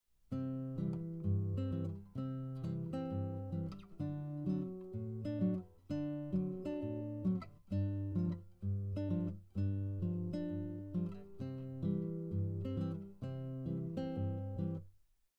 This fingerpicking style is characterized by a repeated bass pattern, while playing chords and melody at the same time.
At this point, you will hear the characteristic sound of Travis picking which includes syncopated melody notes above the chords and bass.
Travis picking pattern 4: syncopated melody notes